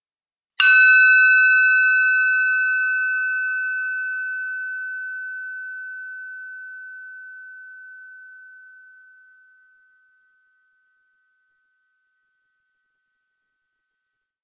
Bronze Bell 3
bell bronze chime ding percussion ring stereo xy sound effect free sound royalty free Sound Effects